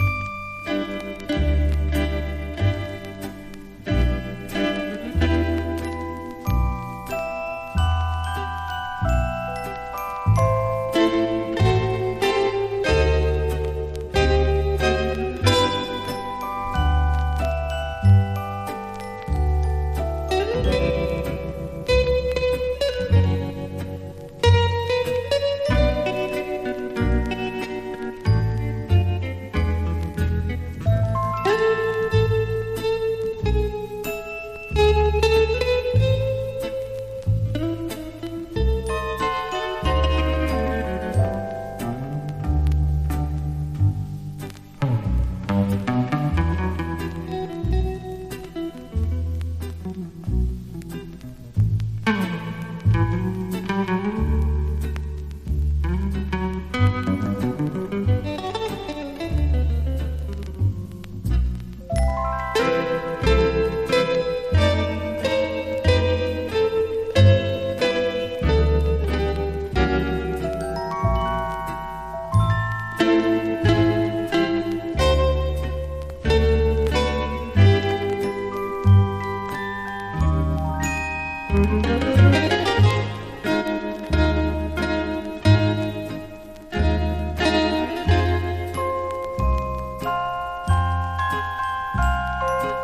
和ジャズ・ギターのトップ二人による1967年の共演盤！